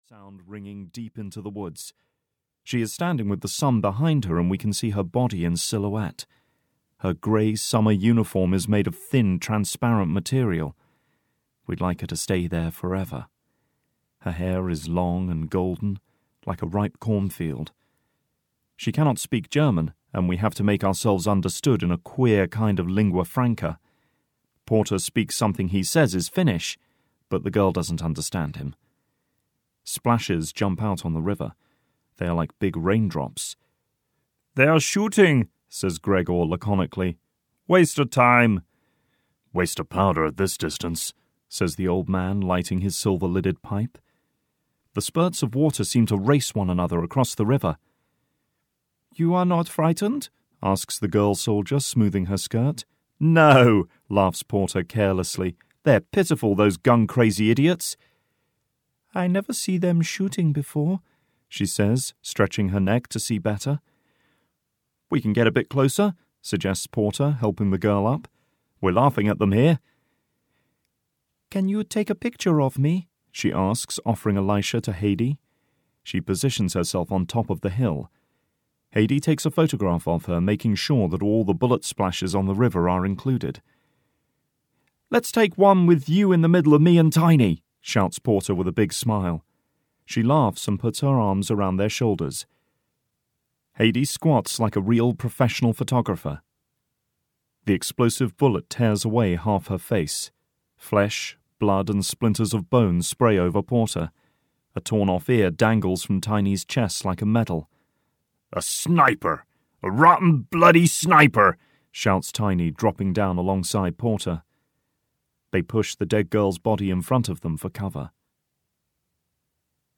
Court Martial (EN) audiokniha
Ukázka z knihy